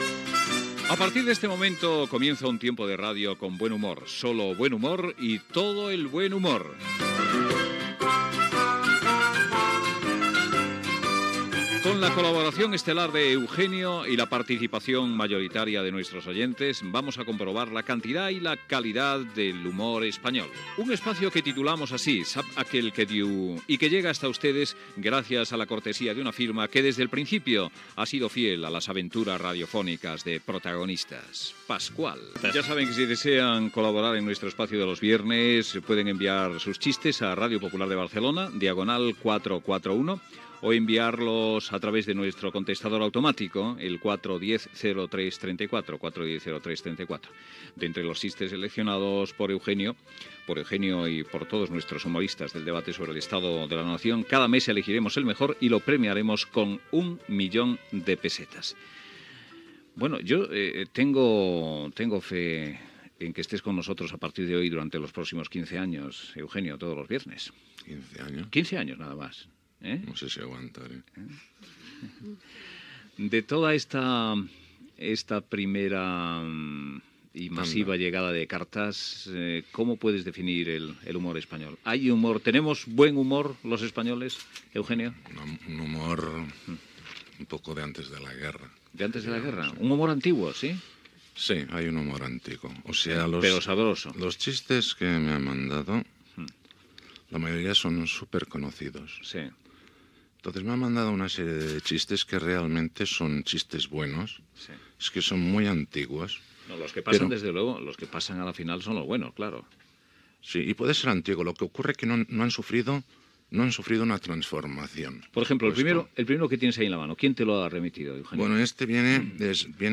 Fragment de la primera secció "Saben aquell que diu" con el humorista Eugenio.
Info-entreteniment